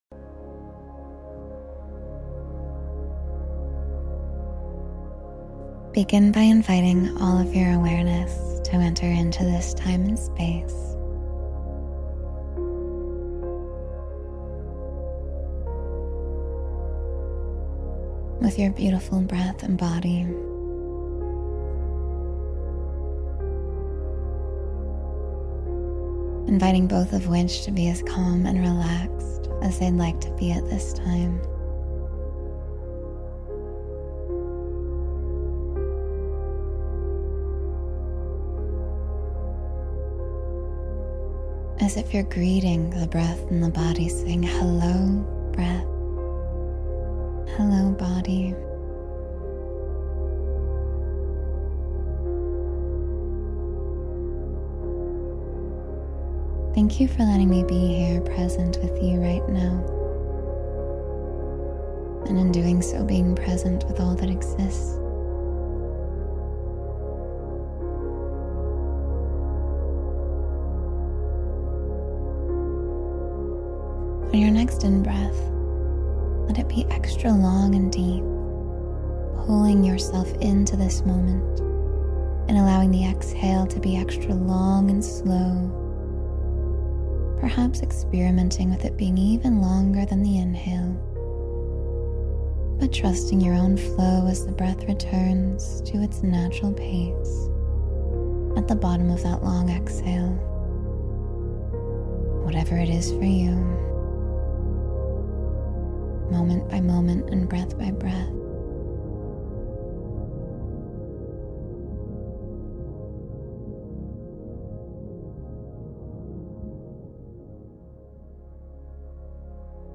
Drift into Sleep with Deep Relaxation — Meditation for Restful Sleep